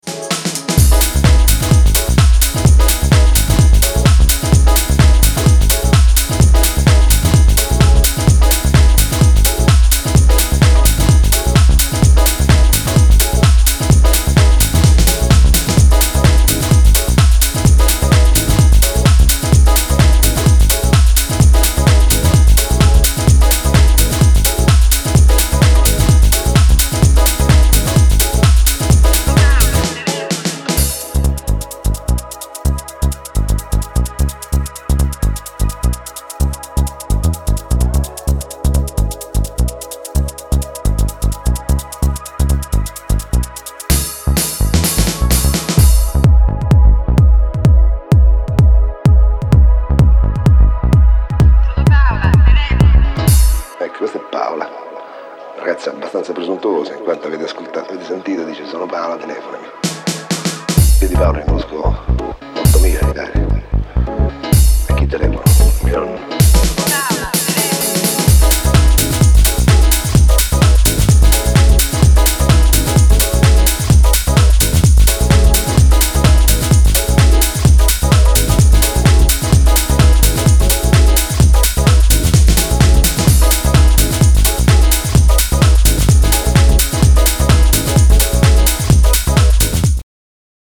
いずれも、90s前半のイタリアン・ハウスの情緒的な部分までもモダンなプロダクションできちんと汲み取った意欲作。